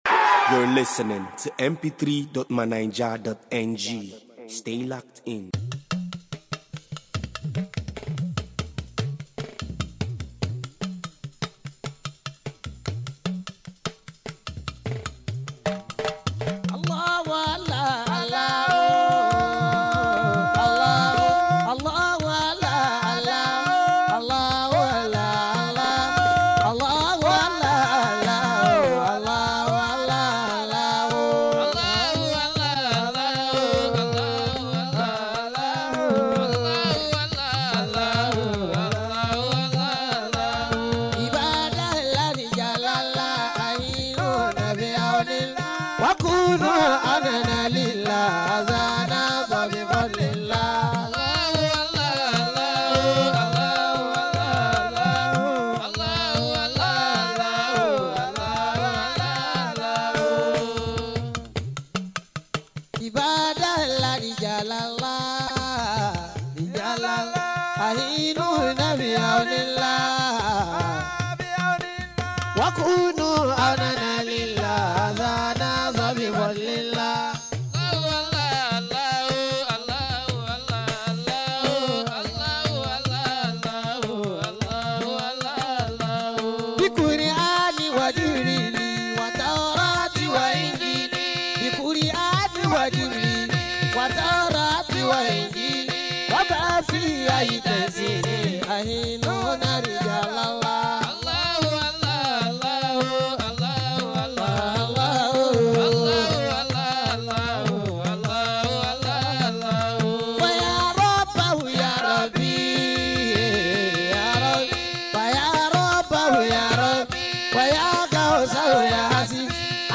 Ramadan Lecture